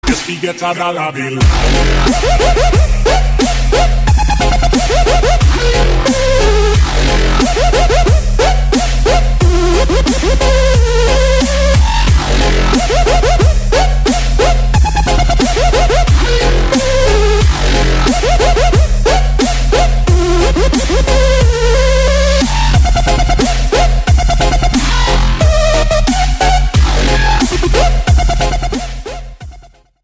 • Качество: 192, Stereo
Electronic
EDM
drum n bass
Дабстеп
Стиль: dubstep, drum'n'bass